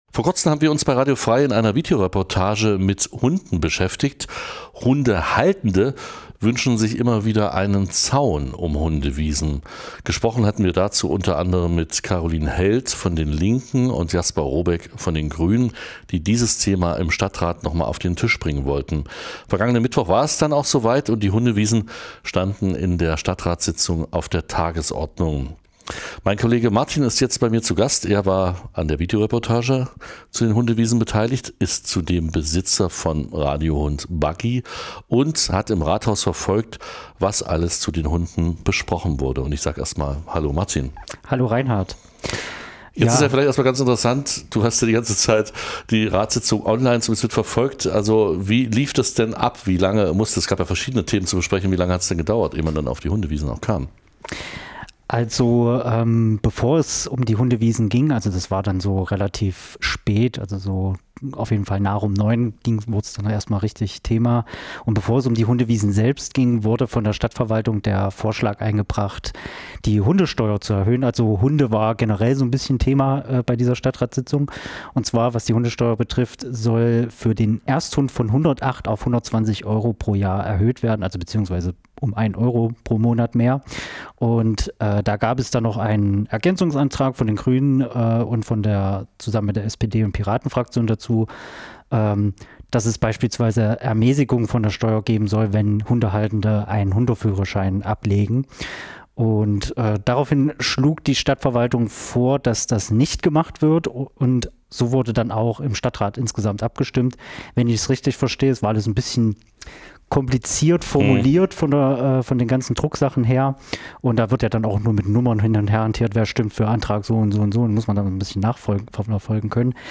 Korrektur: Im Gespr�ch wird gesagt, dass Steuerbeg�nstigungen f�r das Absolvieren des Hundef�hrerscheins abgelehnt wurden.